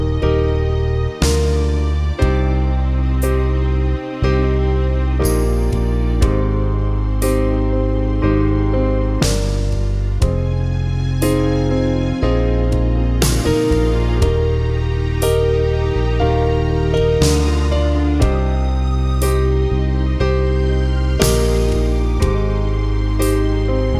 One Semitone Down Pop (2010s) 4:10 Buy £1.50